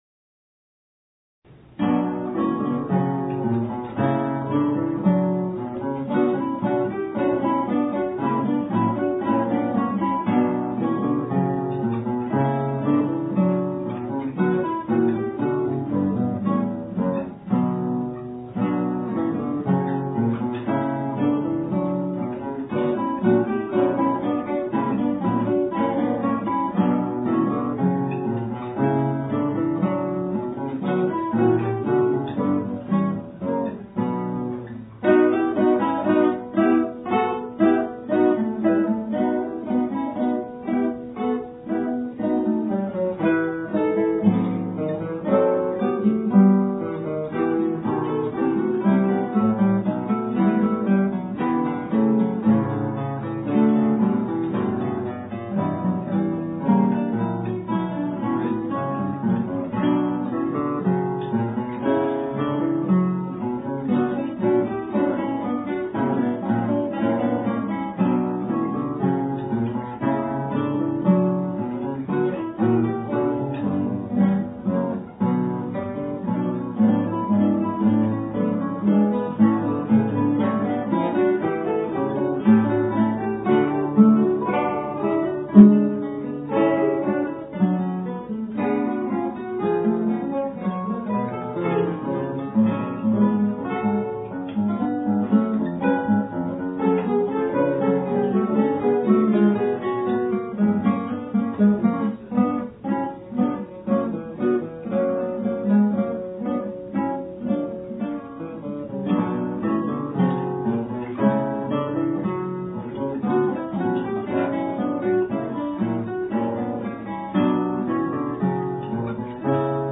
アンサンブルｐｉｍａ（府大クラシックギタークラブＯＢ有志）
練習風景（２００３年０９月１５日撮影）　クラブ同期の有志６人で３５年ぶりに一泊二日で合奏をやりました。